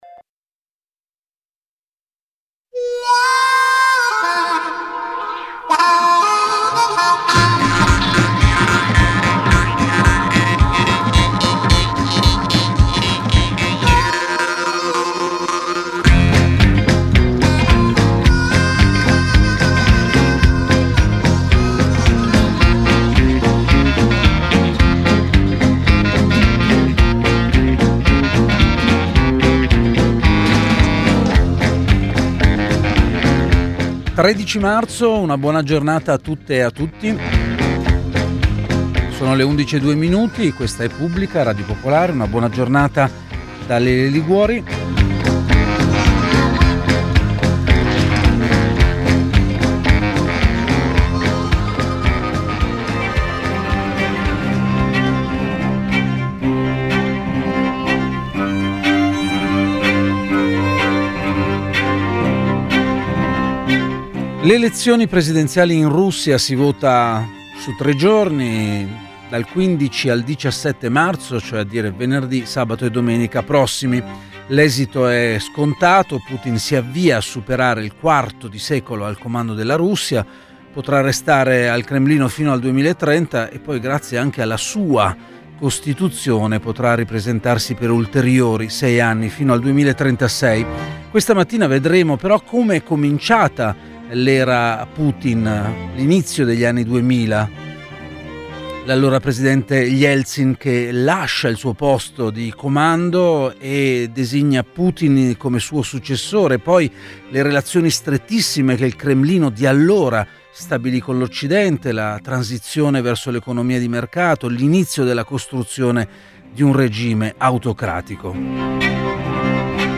A Pubblica due scienziate politiche e studiose della Russia ci portano alle origini dell’era Putin